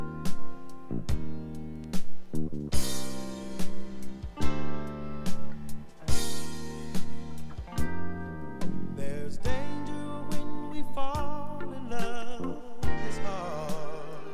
Recorded Signal from Vinyl Thin, Weak, Skinny
The vinyl sounds alright, maybe a little muddied depending on the disc, but the audio recording does not look right. The recording looks weak and thin, and does not have the substance I would expect.